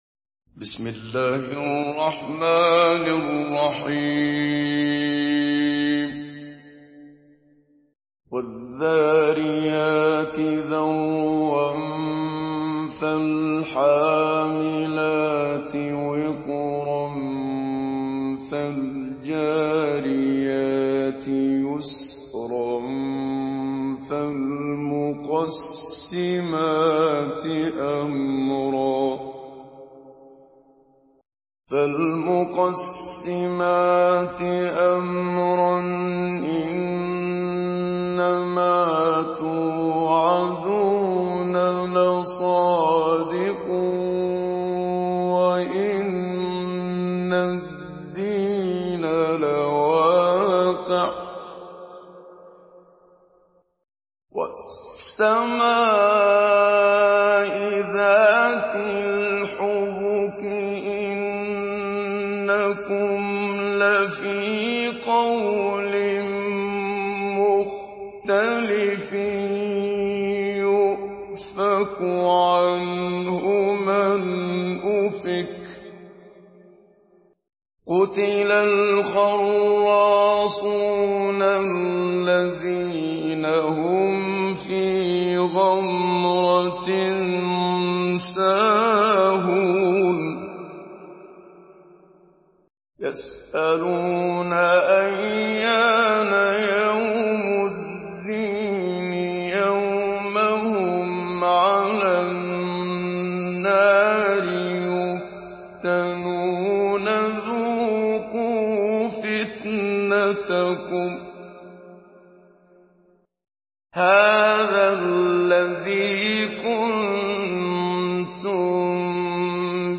Surah الذاريات MP3 in the Voice of محمد صديق المنشاوي مجود in حفص Narration
Listen and download the full recitation in MP3 format via direct and fast links in multiple qualities to your mobile phone.